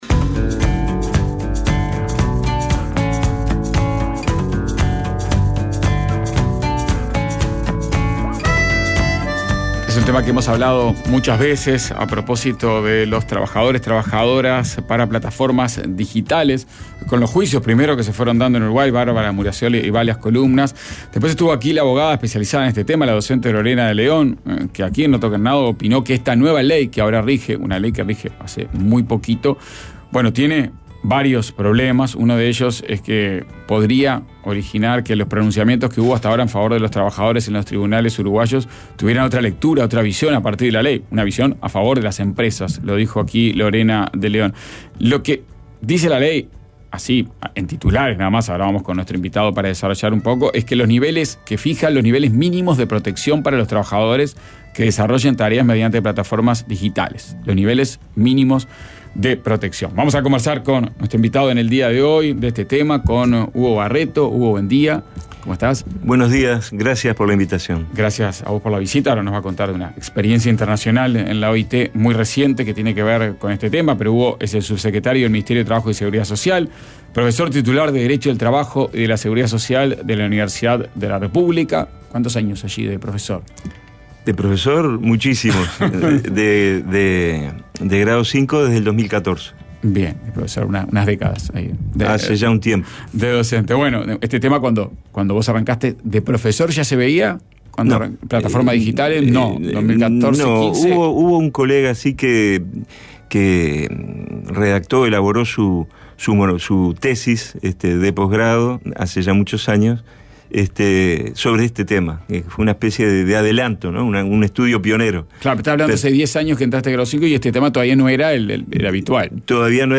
Entrevista y música en vivo